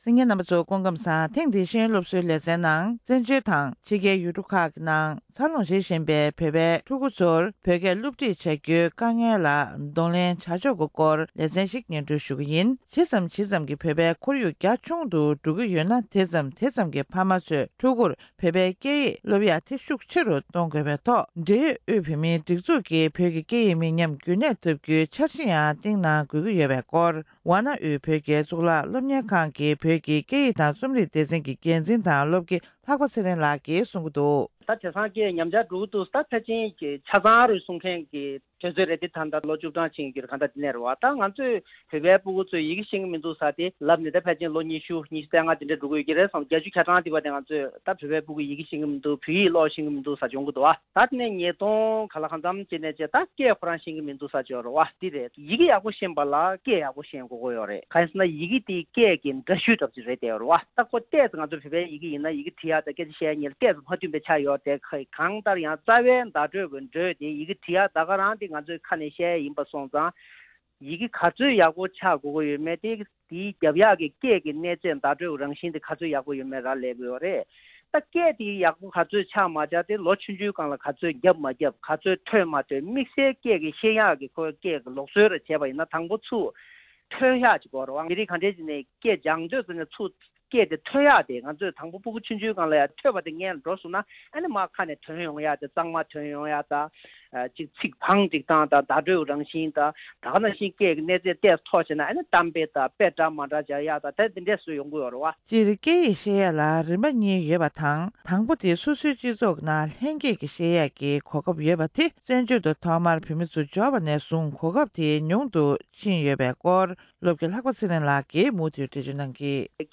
བཙན་བྱོལ་ནང་བོད་སྐད་བེད་སྤྱོད་ཐད་འཕྲད་བཞིན་པའི་དཀའ་གནད་དང་བོད་པའི་སྐད་ཡིག་མི་ཉམས་རྒྱུན་འཛིན་གནས་ཐུབ་པའི་ཐབས་ལམ་སྐོར་བཅར་འདྲི་ཞུས་པ།